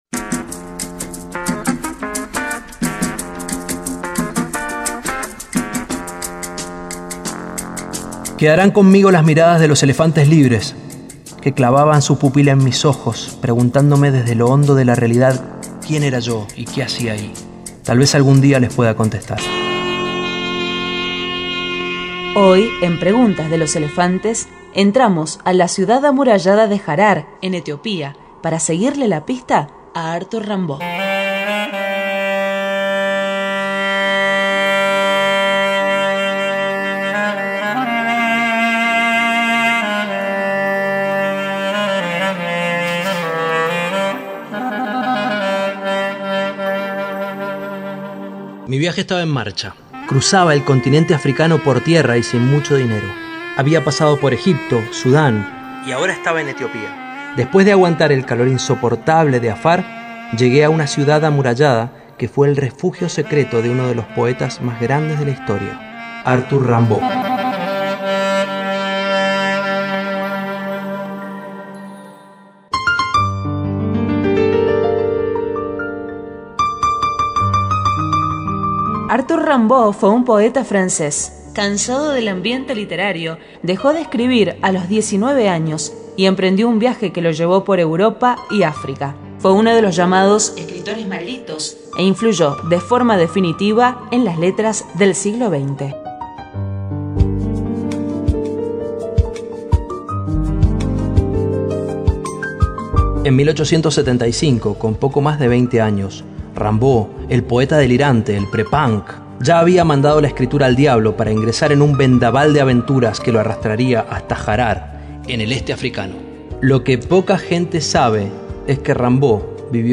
Headliner Embed Embed code See more options Share Facebook X Subscribe Cap. 5 - Etiopía III: Harar, el refugio del poeta En este capítulo le seguimos la pista a Arthur Rimbaud, el genio que dejó de escribir antes de los veinte años para devenir traficante de armas en África. Desde Harar (Etiopía), consultaremos en los mercados y en las calles si alguien sabe qué fue de él.